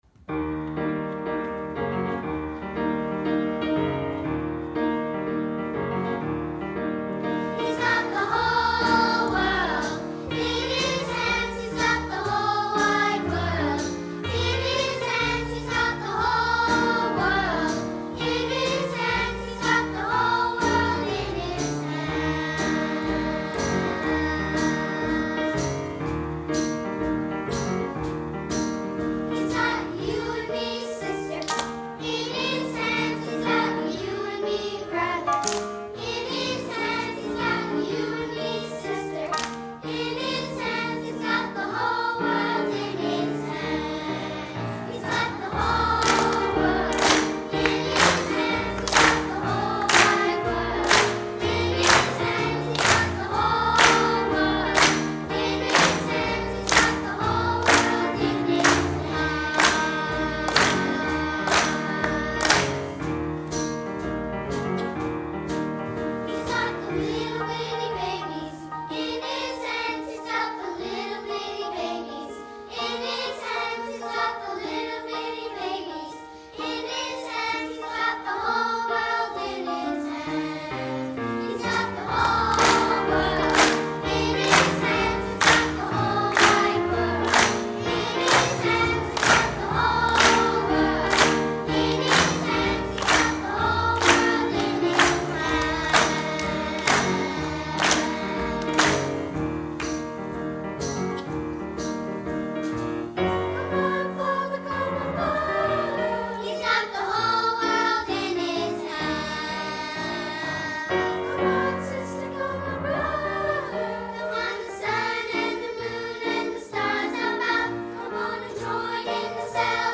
for Treble Voices and Piano (1999)
for SATB Chorus and Piano (2008)